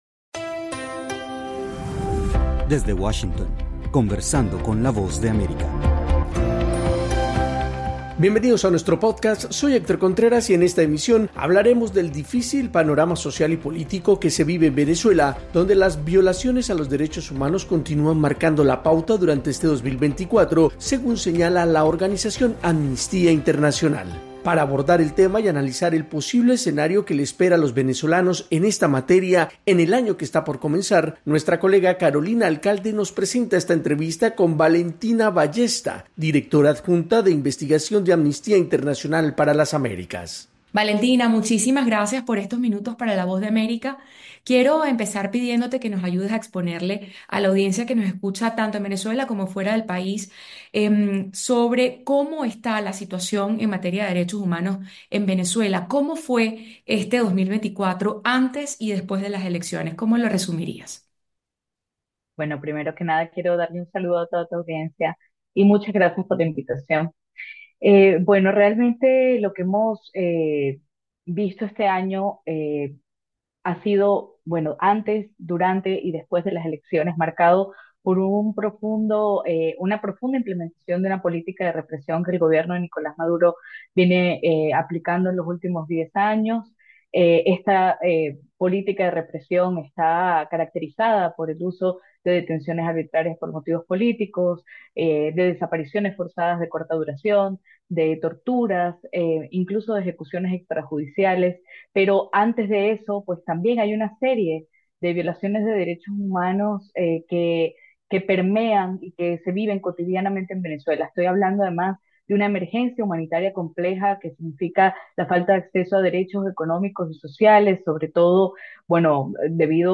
En Conversando con la Voz de América exploramos la situación de los derechos humanos en Venezuela en 2024 y cuáles son las expectativas para el próximo año. Para ampliar el tema conversamos con